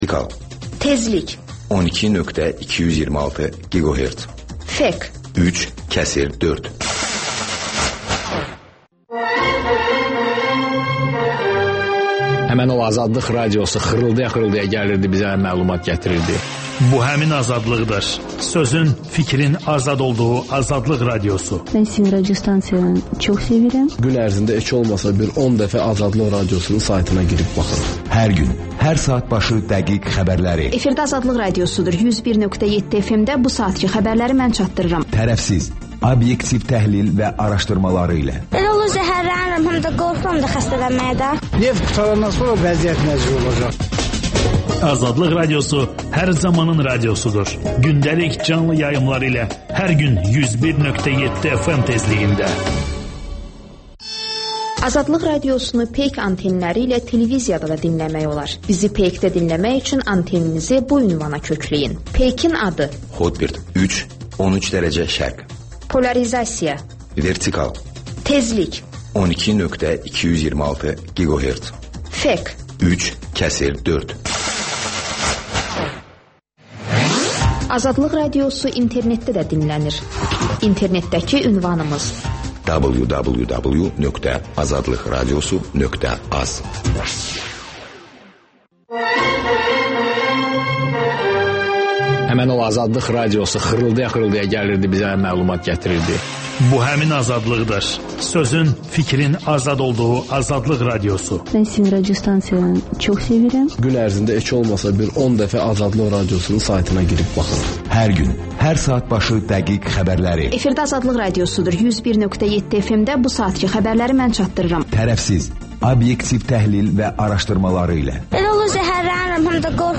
Müxbirlərimizin həftə ərzində hazırladıqları ən yaxşı reportajlardan ibarət paket (Təkrar)